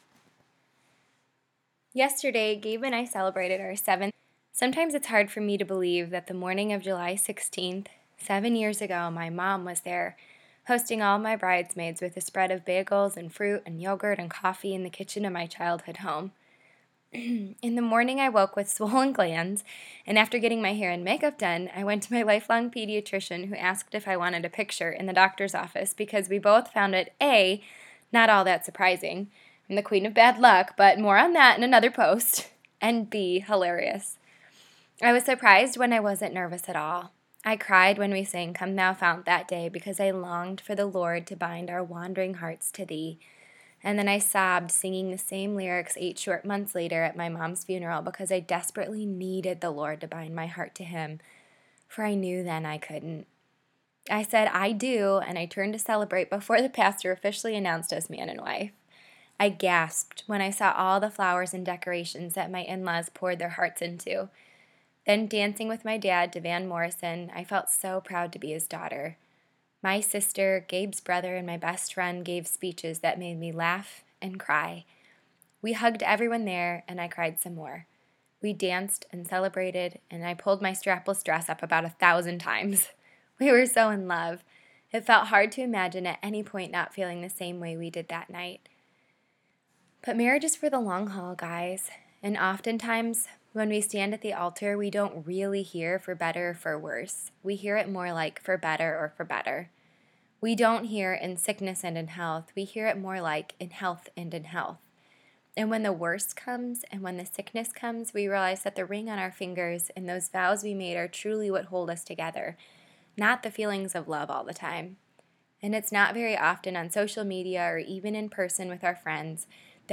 (Just in case you’re in a rush or you’re driving or whatever, and you just need to be read to–here’s me imperfectly reading this post word for word.)